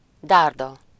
Ääntäminen
IPA : /spɪə̯(ɹ)/